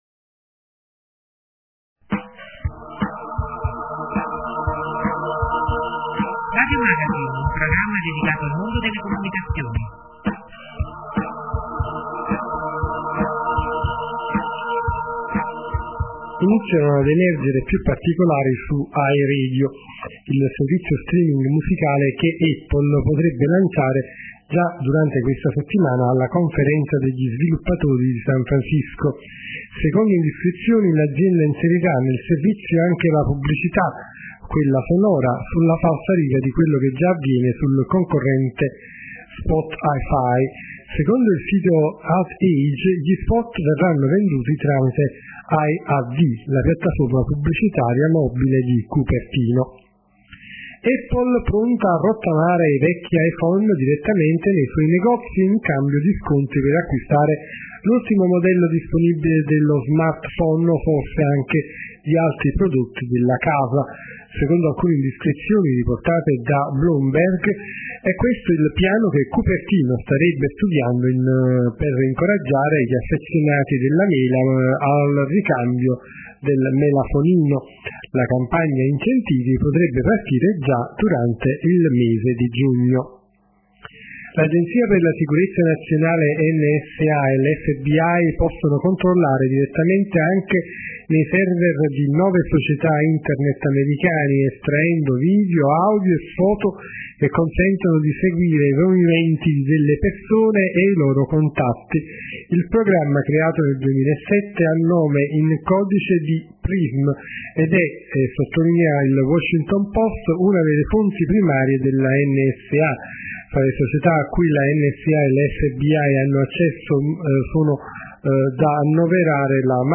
la nostra sintesi vocale